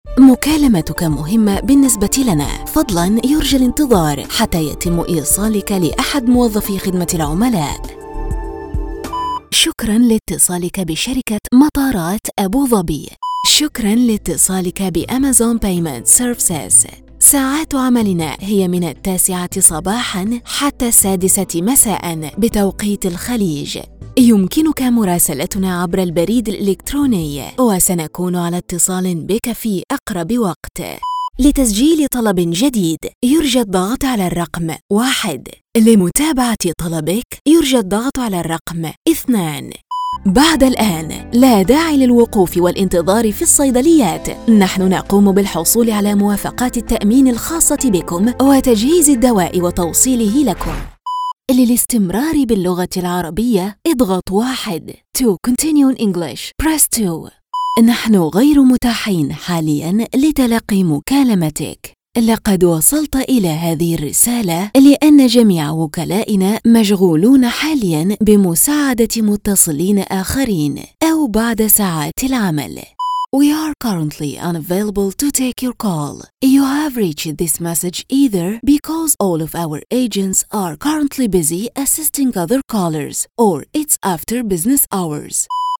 Female
Phone Greetings / On Hold
All our voice actors have professional broadcast quality recording studios.
1102Telephony.mp3